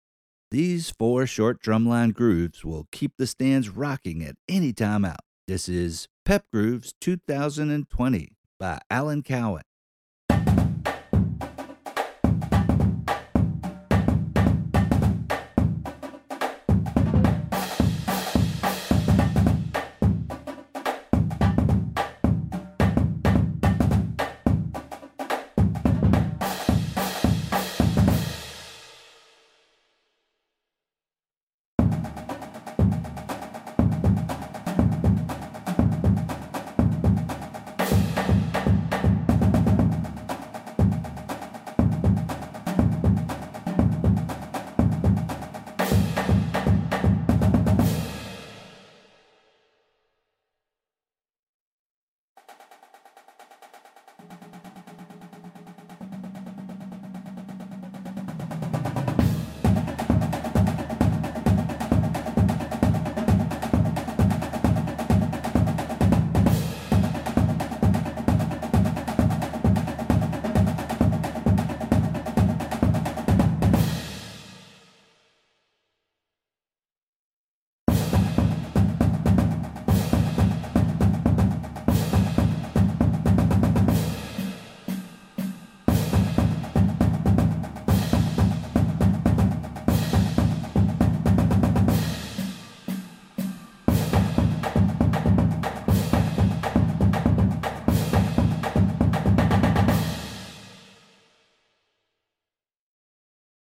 Voicing: Percussion Cadence